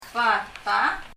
rose バラ » Abogado アボガド bata [bata] 英） abogado 日) アボガド Leave a Reply 返信をキャンセルする。